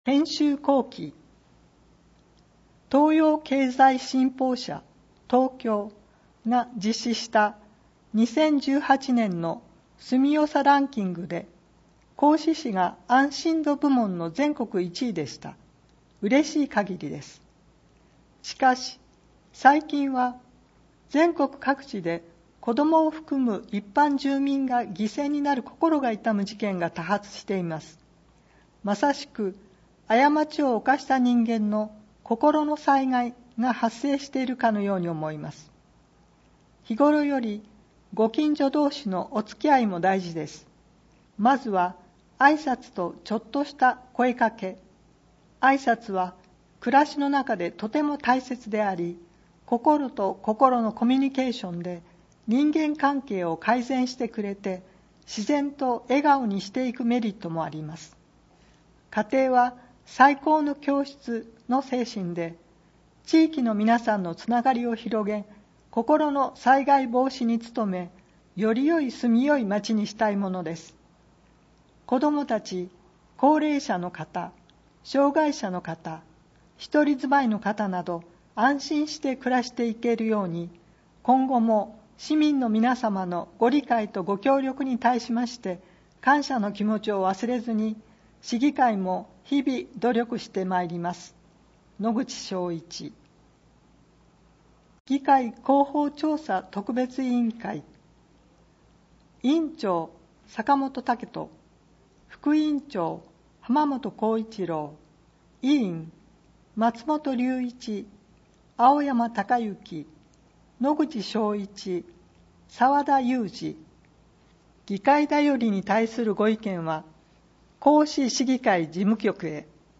音声訳版 議会だより第５０号